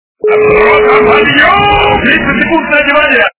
» Звуки » Люди фразы » Прапорщик кричит на солдат - Рота подьем!
При прослушивании Прапорщик кричит на солдат - Рота подьем! качество понижено и присутствуют гудки.